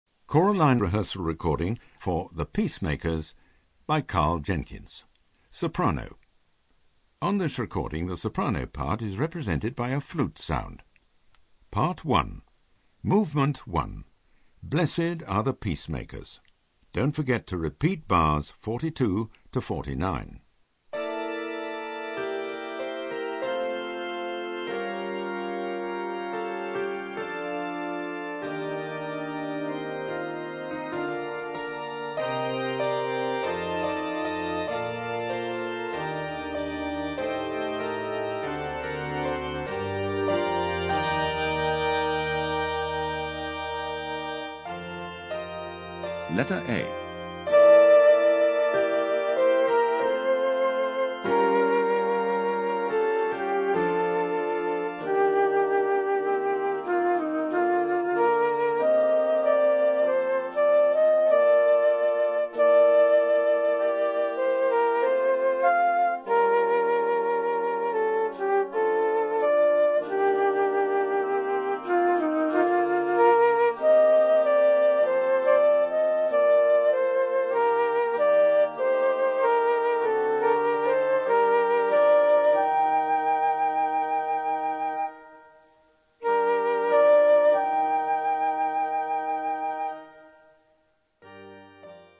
Soprano
Easy To Use narrator calls out when to sing
Don't Get Lost narrator calls out bar numbers
Vocal Entry pitch cue for when you come in